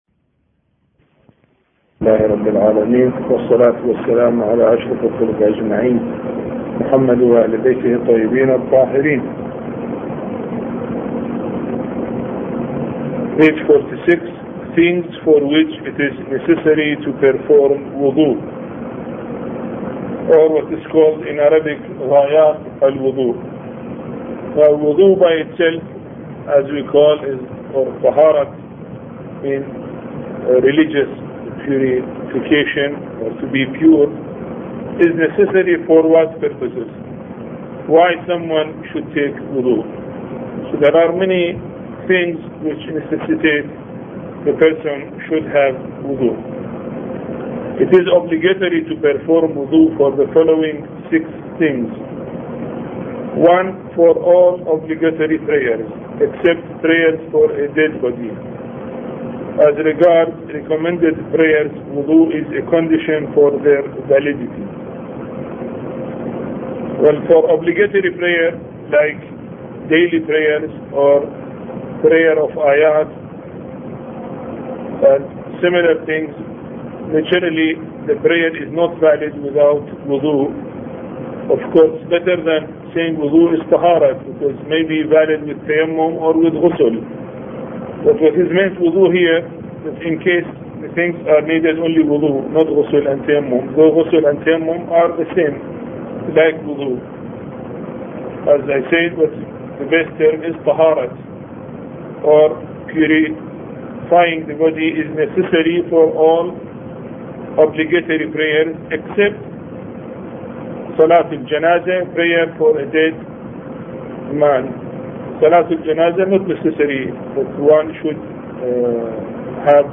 A Course on Fiqh Lecture7